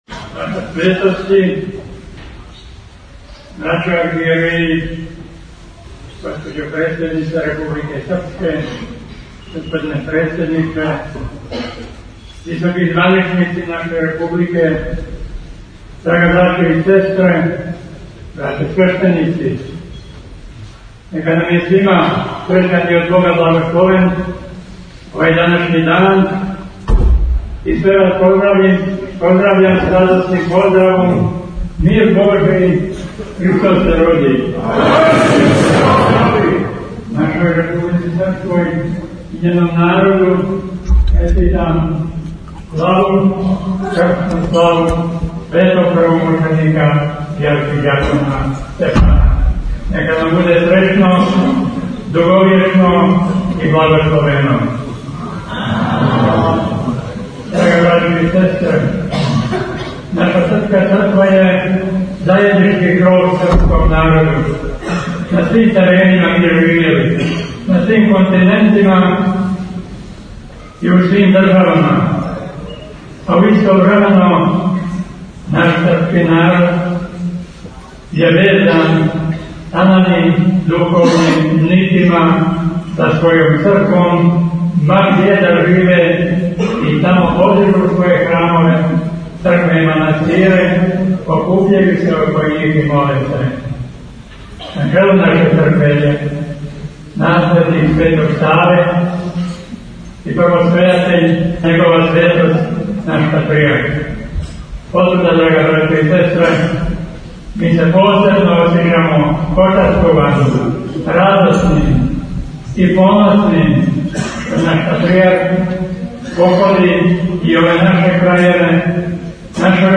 Његова Светост Патријарх српски г. Иринеј служио је 9. јануара 2019. године, на празник Светог архиђакона Стефана – крсну славу Републике Српске, свету архијерејску Литургију у Саборном храму Христа Спаситеља у Бања Луци.
Patrijarh-Banja-Luka-2019-za-stranicu.mp3